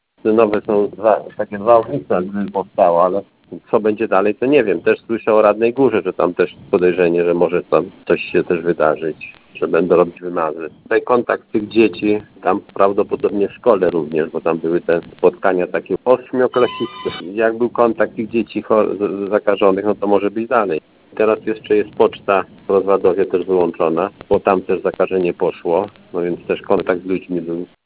Starosta stalowowolski Janusz Zarzeczny przyznaje, że to bardzo trudna sytuacja.